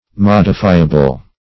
Modifiable \Mod"i*fi`a*ble\, a. [From Modify.]